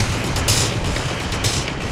RI_DelayStack_125-01.wav